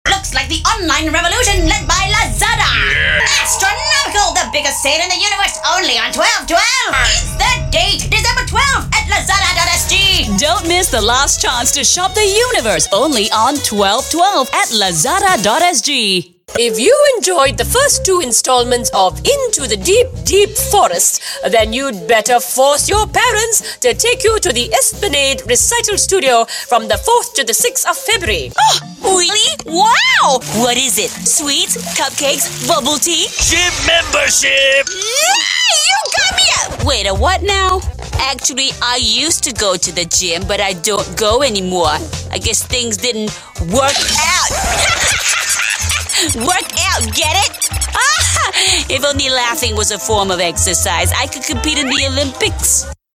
Voice Samples: Character
EN Asian
female